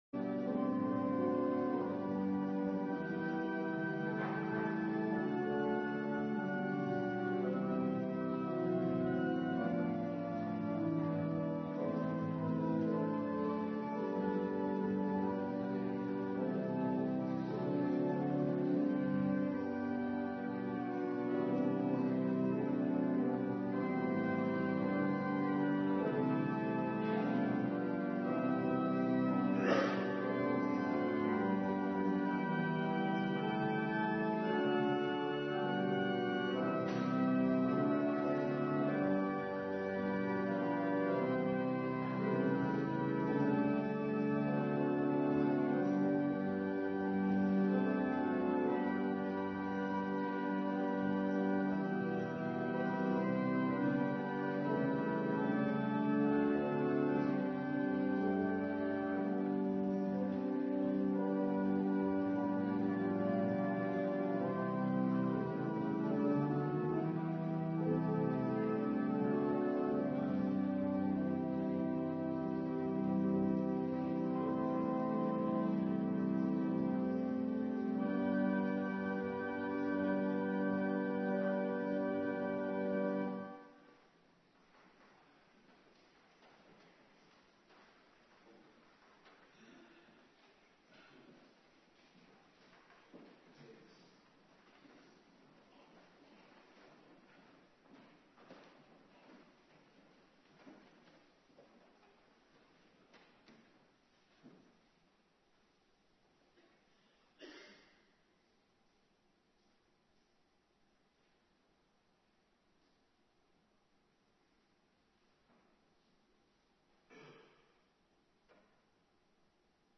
Woensdagavonddienst
19:30 t/m 21:00 Locatie: Hervormde Gemeente Waarder Agenda